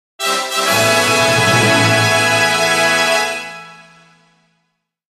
The sound that plays when going up a level